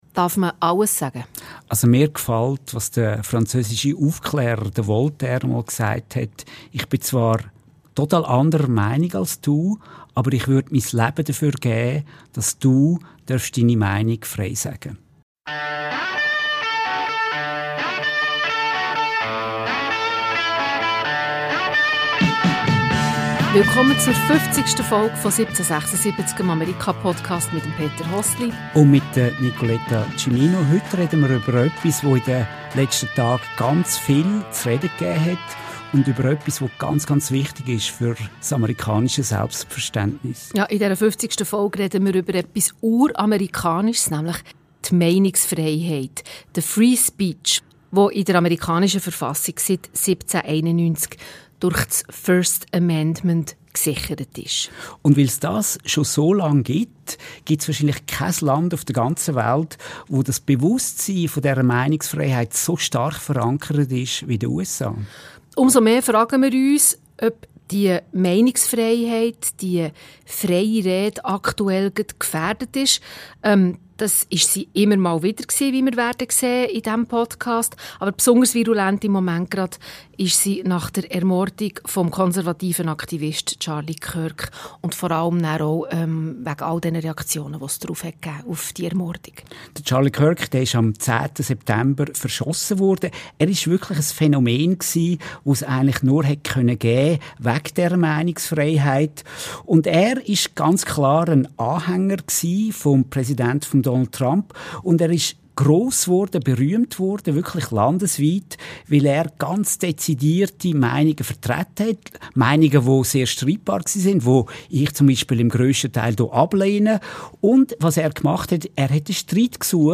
Ein Gespräch über 234 Jahre amerikanische Redefreiheit: von den Gründervätern bis zu Donald Trump See all episodes